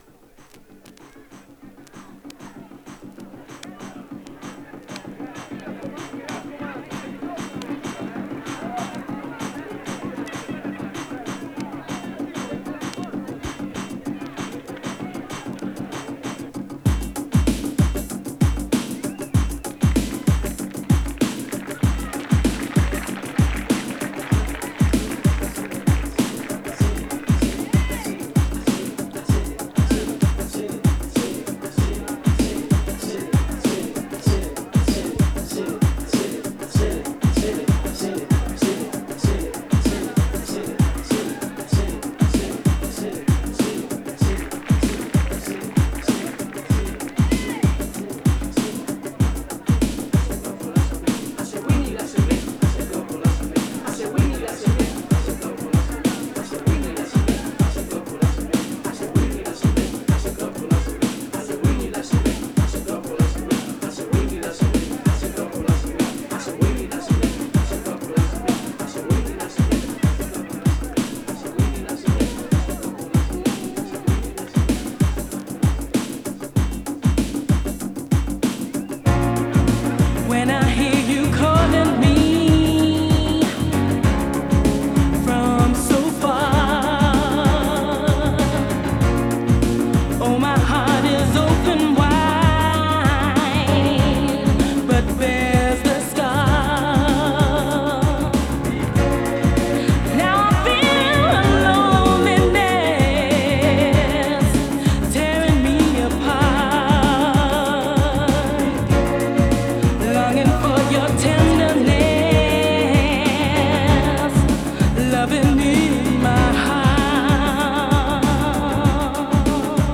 民族 壮大 グランドビート
トライバルで壮大な雰囲気が漂うグランドビート！